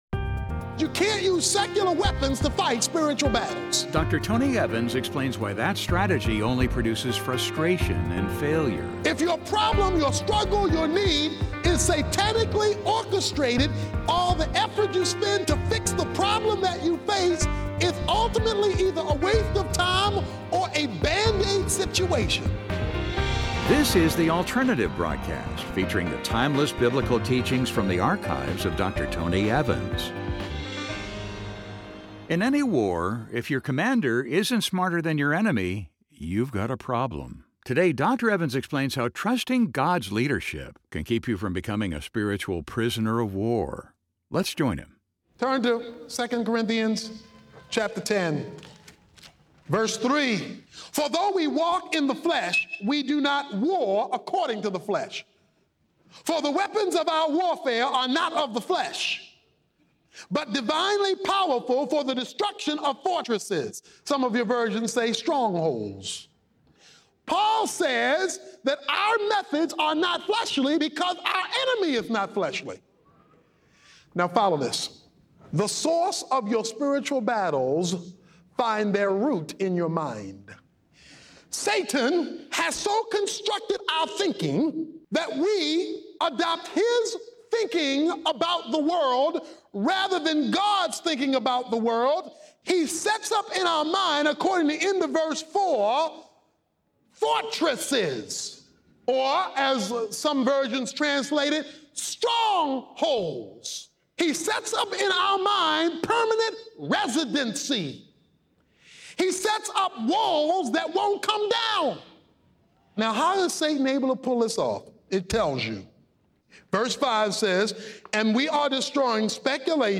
In any war, if your commander isn't smarter than your enemy, you've got a problem. In this message, Dr. Tony Evans explains how trusting God's leadership can keep you from becoming a spiritual "Ëœprisoner of war'.